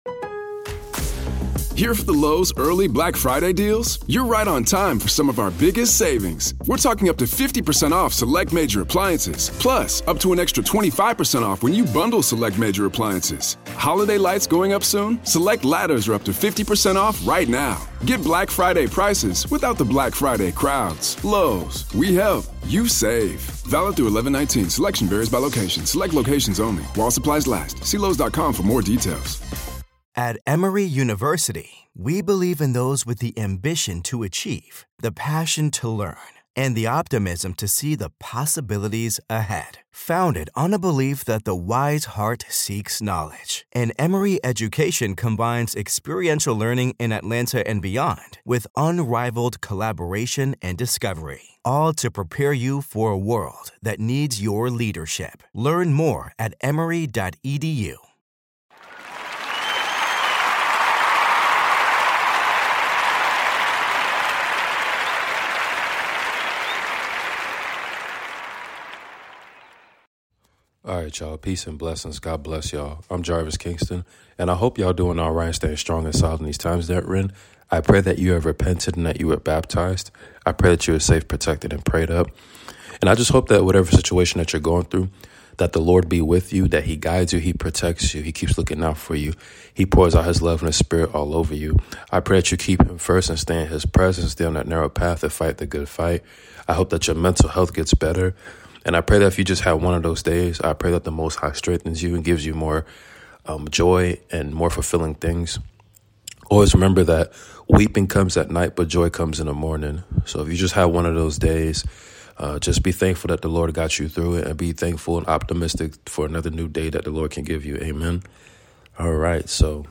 Continued reading of the book of Genesis!